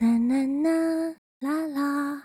普通.wav 0:00.00 0:02.25 普通.wav WAV · 194 KB · 單聲道 (1ch) 下载文件 本站所有音效均采用 CC0 授权 ，可免费用于商业与个人项目，无需署名。
人声采集素材/人物休闲/普通.wav